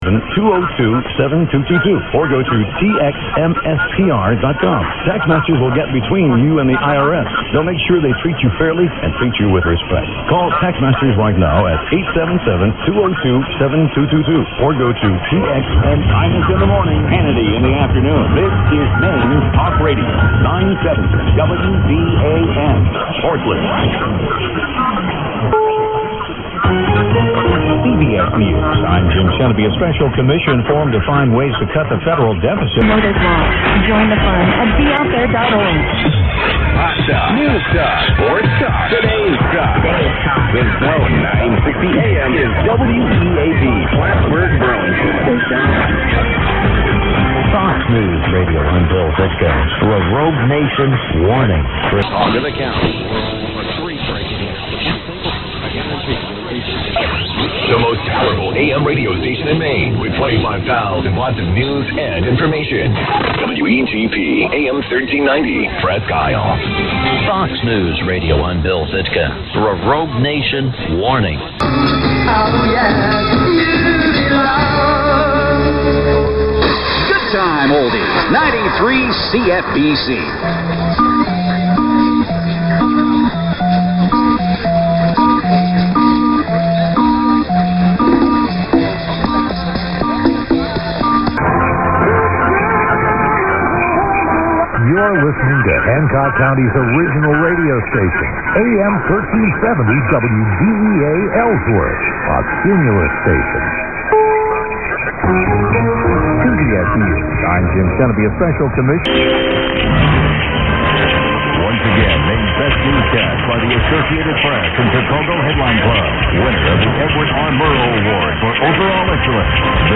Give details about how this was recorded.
I have created a montage of the band today, at various times overnight, by way of an explanation of everyday dx heard here in the west of Scotland at this time of year. You can see some that are often booming are mushy, like WTIC and WBBM.